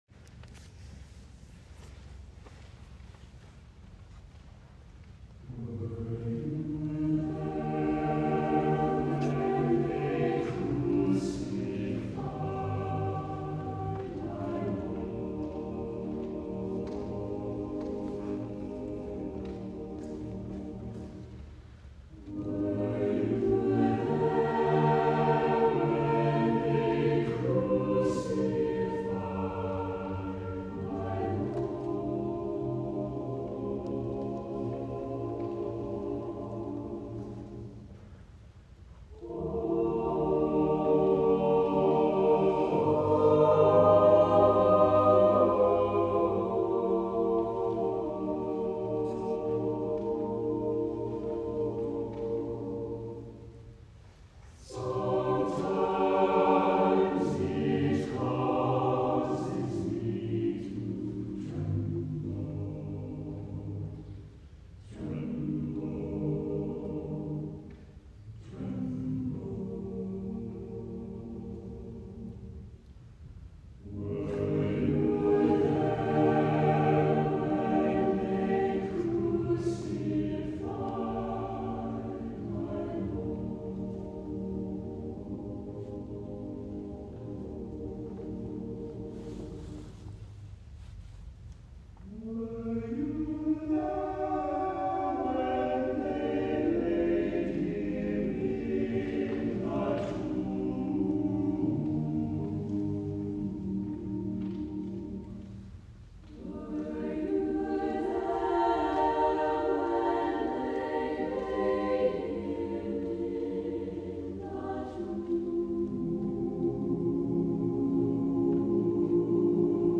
“Were You There” by Norman Luboff is one of the most convicting pieces I have ever sang in a choir.